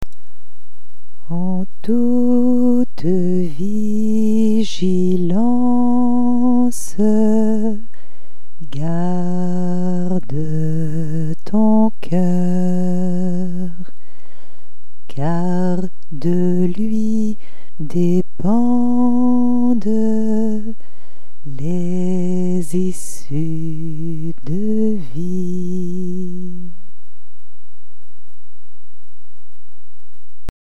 Versets chantés en récitatif